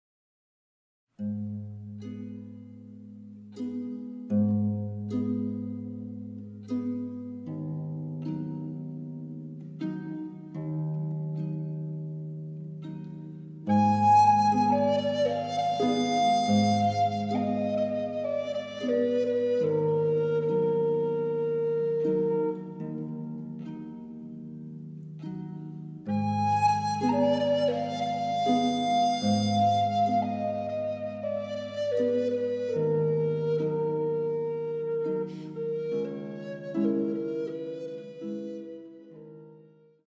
Harpe, Violoncelle, Flûtes à bec et Viole de Gambe